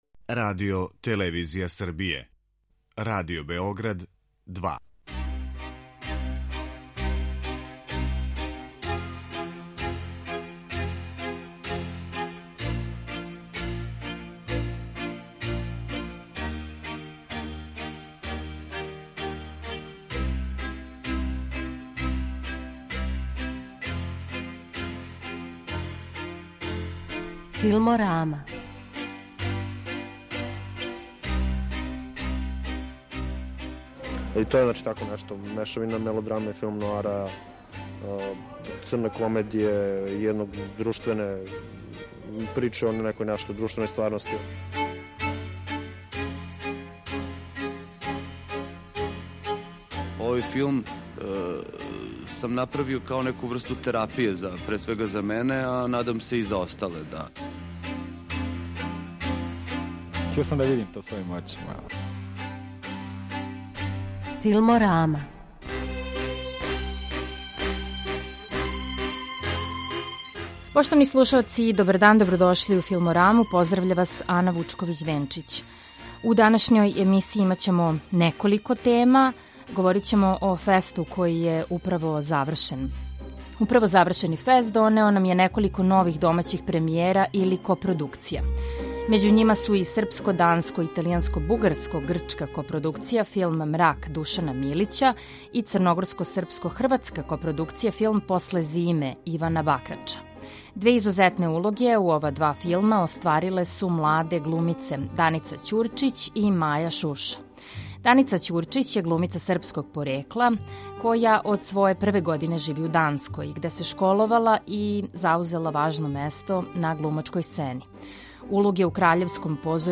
Сутра увече ће премијерно бити приказан филм Било једном у Србији Петра Ристовског, па ћемо у данашњој емисији чути неке од чланова екипе ове узбудљиве мелодраме епохе.